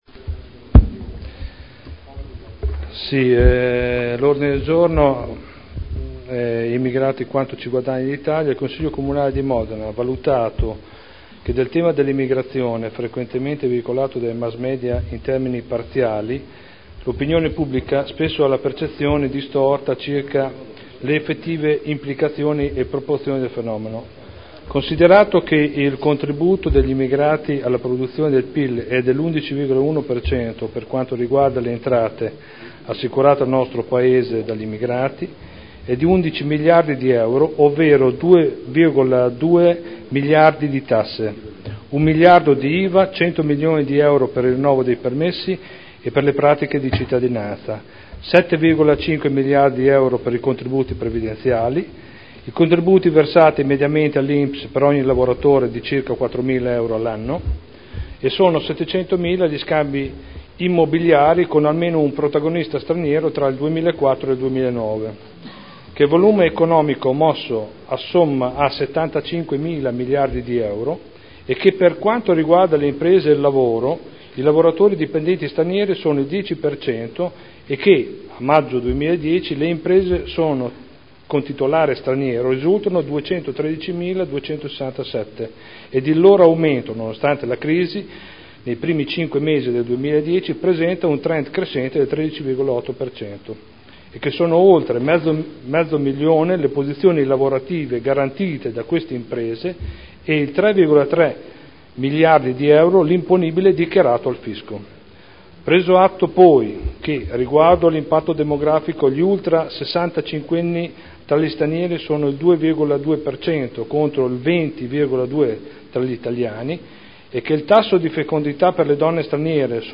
Stefano Prampolini — Sito Audio Consiglio Comunale
Seduta del 19/09/2011. Introduce Ordine del Giorno presentato dai consiglieri Prampolini, Artioli, Rossi F., Goldoni, Dori (P.D.) avente per oggetto: "Immigrati: quanto ci "guadagna" l'Italia"